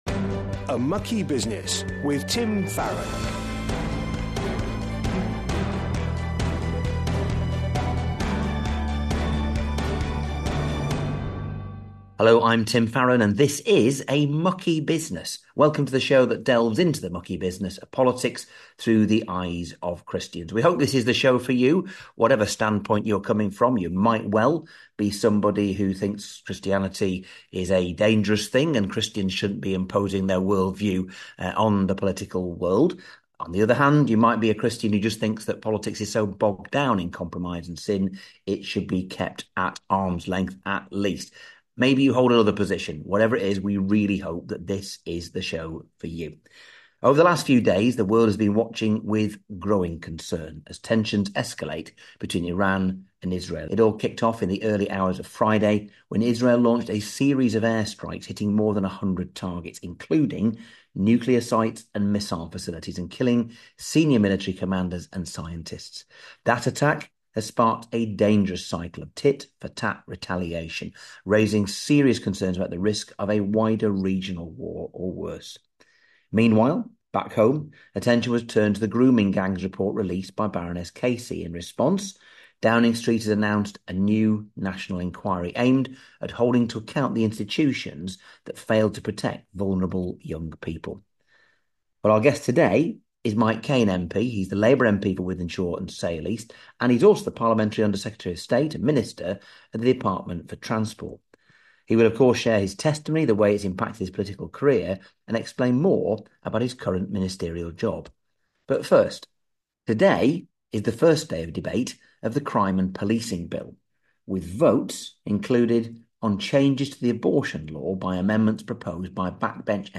Mike shares his personal testimony, talks about his current role in government, and even treats us to a short rendition of the classic hymn Be Thou My Vision - showcasing a bit of his musica…